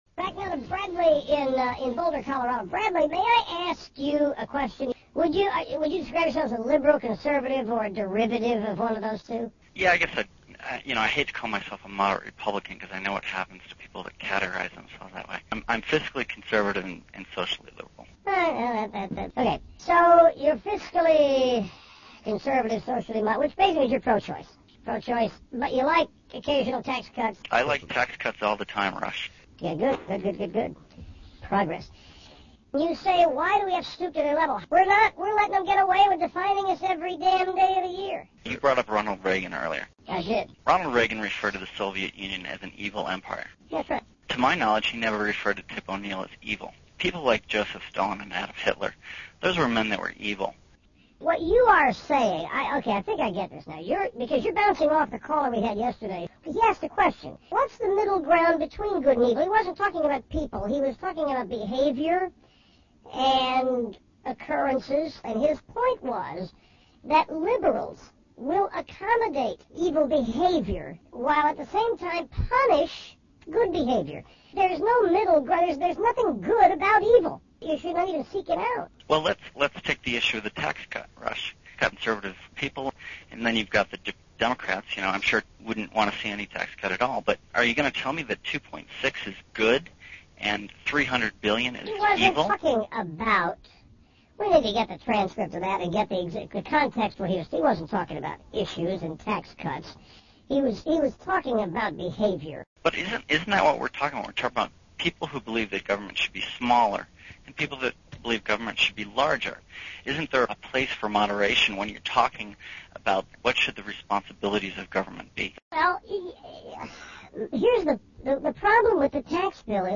Rush Limbaugh as a chipmunk!